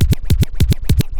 Scratch Steveland 4.wav